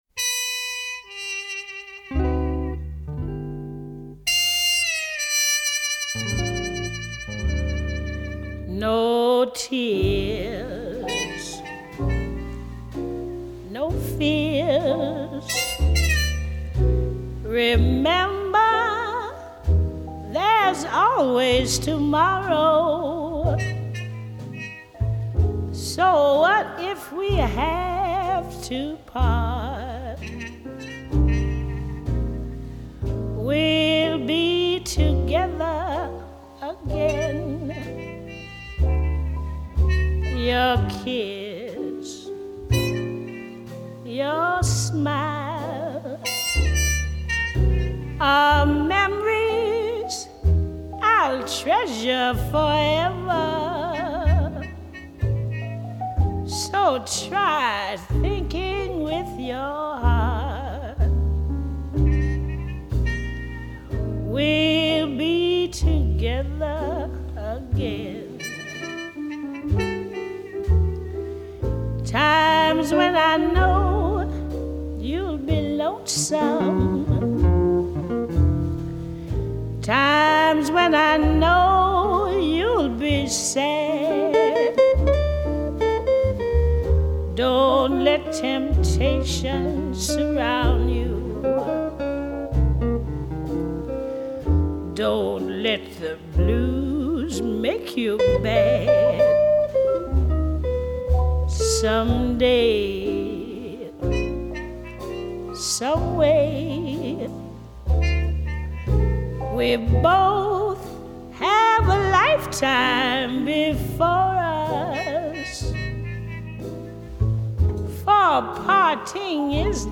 ★ 一生充滿悲情、改變了美國流行歌曲演唱藝術的傳奇爵士女伶，用生命唱出的音符！